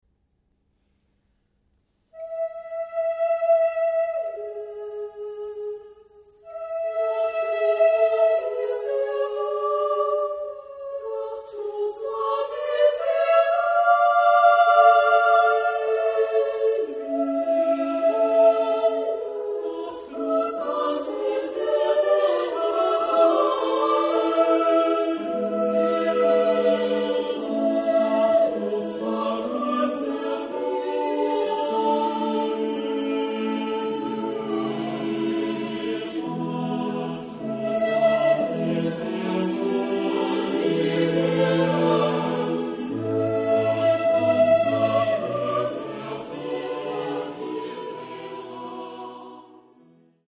Epoque: 17th century  (1600-1649)
Genre-Style-Form: Sacred
Type of Choir: SSATB  (5 mixed voices )
Instrumentation: Continuo  (1 instrumental part(s))
sung by Dresdner Kammerchor conducted by Hans-Christoph Rademann